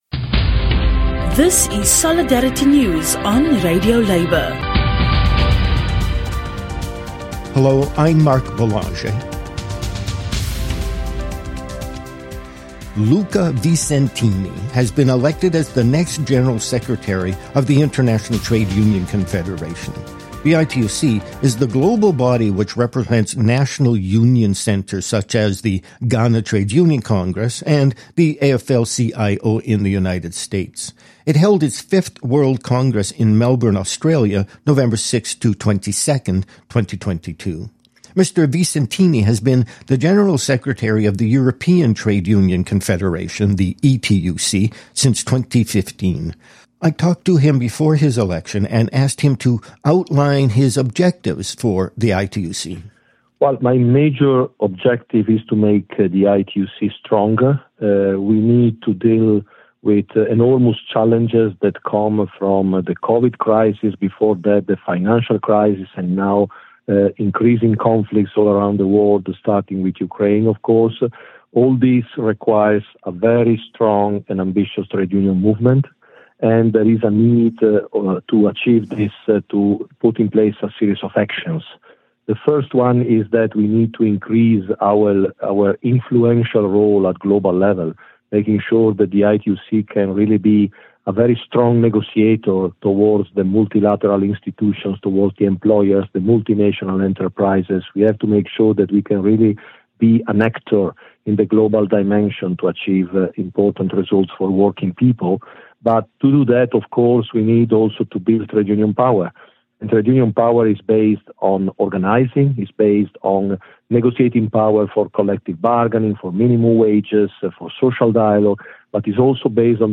Luca Visentini has been elected as the new General Secretary of the International Trade Union Confederation. In an interview with RadioLabour he outlines his objectives for the ITUC and his views on the rise of the far-right.